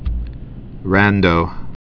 (răndō)